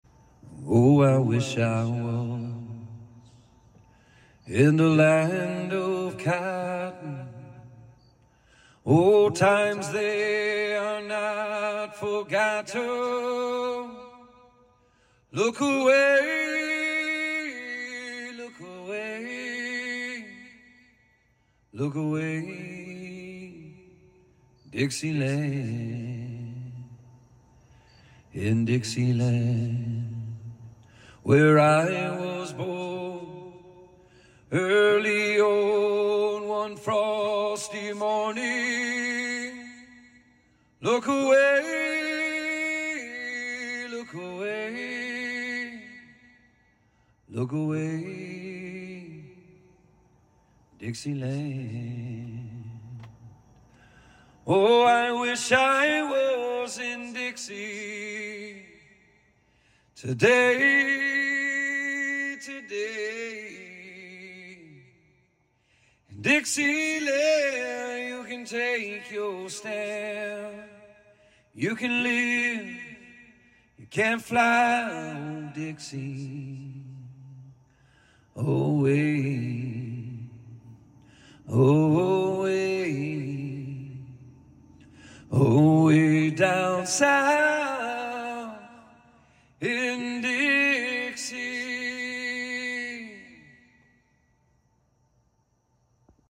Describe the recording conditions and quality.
Back porch iPhone sound effects free download